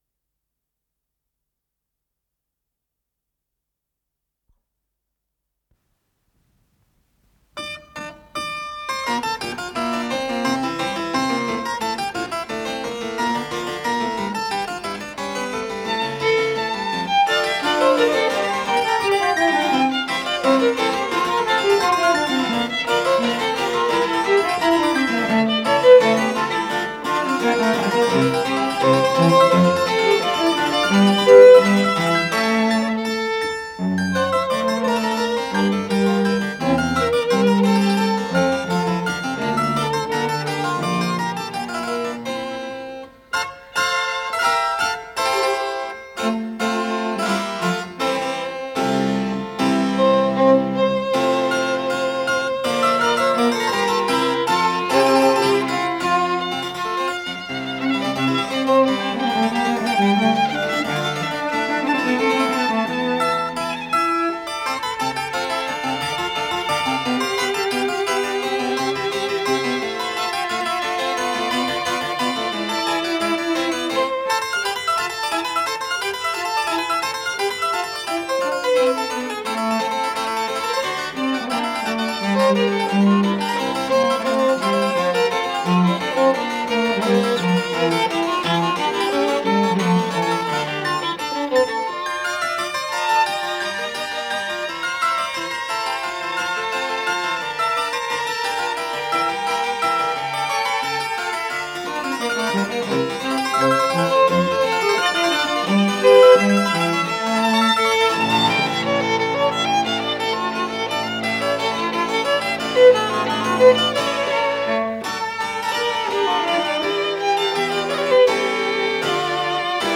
Название передачиКонцерт №5 для клавесина, скрипки и виолы да гамба
Форкерей (фуга)
ИсполнителиИгорь Жуков - клавесин
скрипка
партия виолы да гамба на виолончели